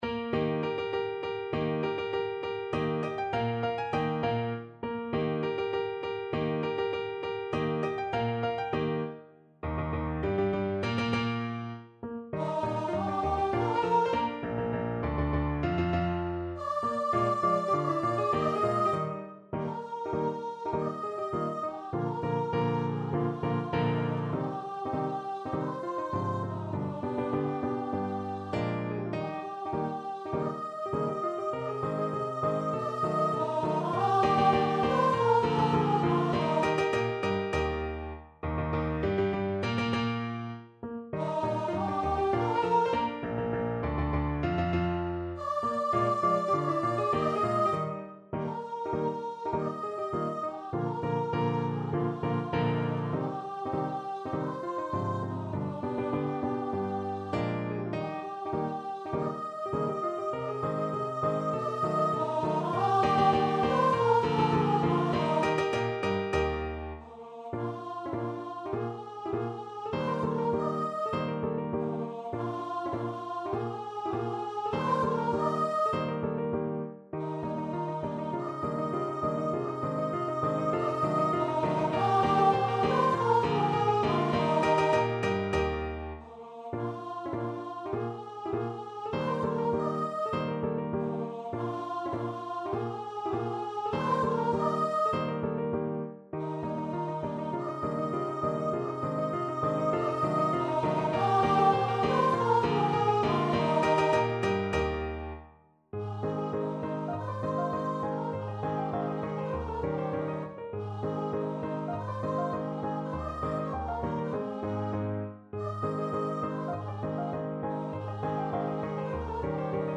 Voice
2/2 (View more 2/2 Music)
Eb major (Sounding Pitch) (View more Eb major Music for Voice )
Traditional (View more Traditional Voice Music)